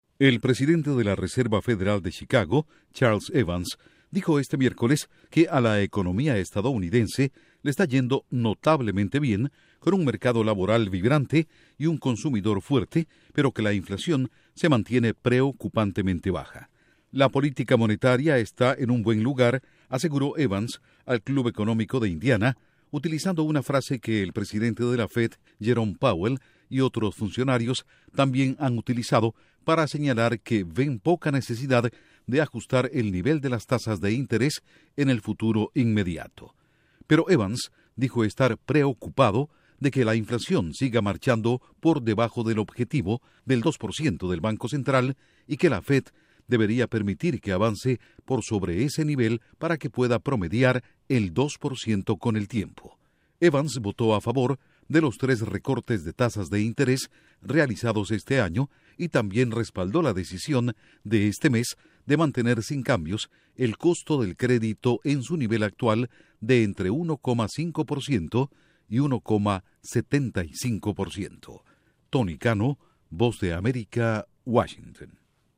ECONOMÍA: Funcionario de Fed: “Economía EE.UU. marcha bien, pero la inflación es muy baja”. Informa desde la Voz de América en Washington